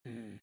描述：Voz de personaje坟墓，没有领带palabras，独唱儿子sonidos de rabia。